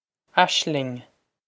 Aisling Ash-ling
This is an approximate phonetic pronunciation of the phrase.